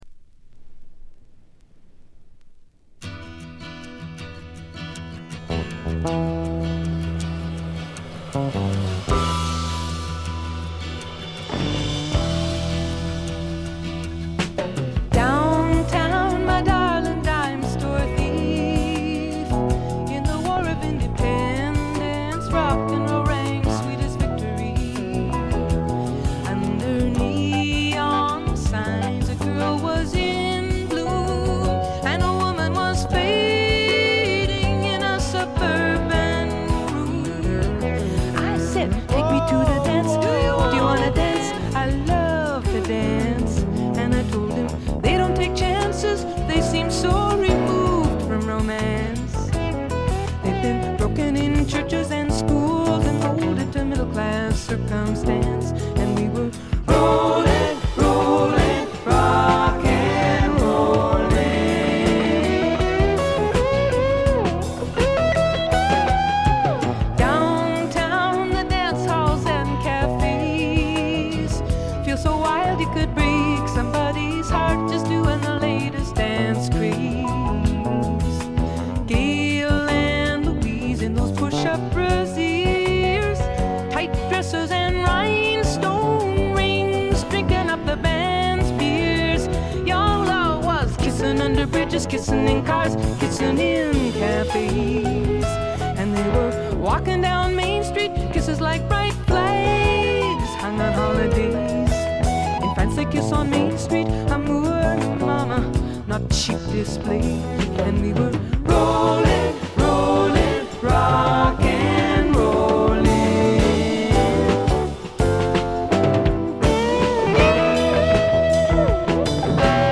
部分試聴ですが、軽微なチリプチ少し。
ここからが本格的なジャズ／フュージョン路線ということでフォーキーぽさは完全になくなりました。
女性シンガーソングライター名作。
試聴曲は現品からの取り込み音源です。